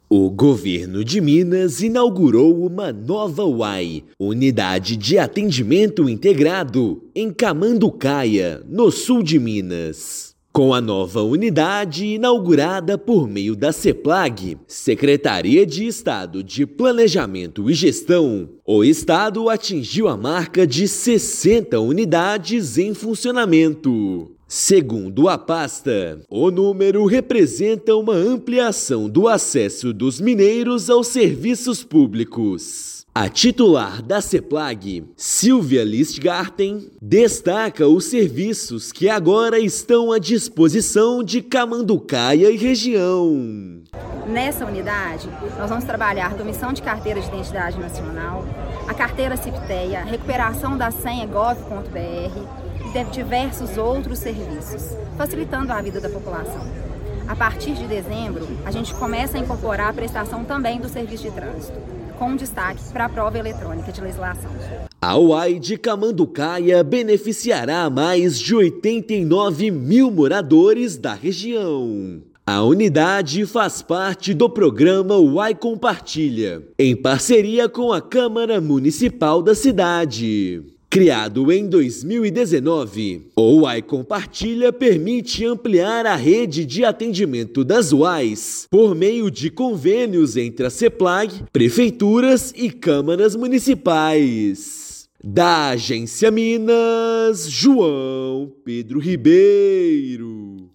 Nova UAI implementada pela Seplag-MG beneficia mais de 89 mil moradores do Sul de Minas com acesso a serviços públicos. Ouça matéria de rádio.